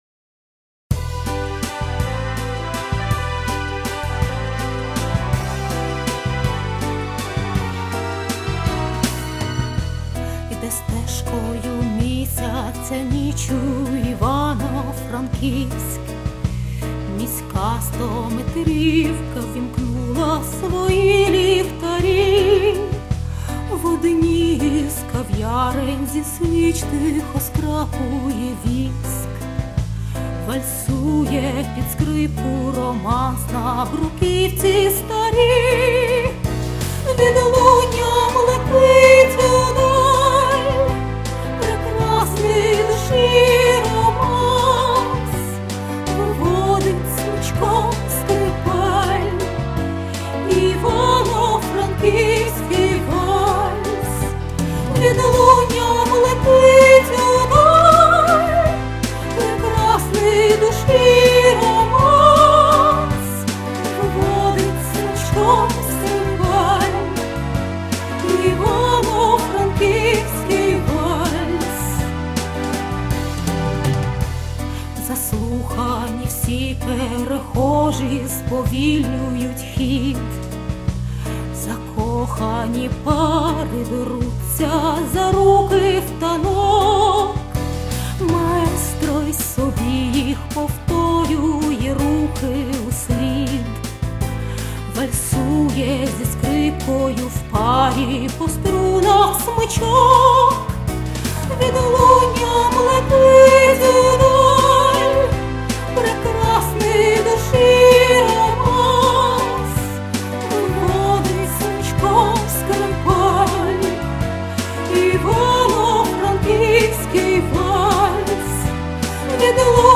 красива пісня і красиве місто, наче там побувала 23 39 і вальс танцювала!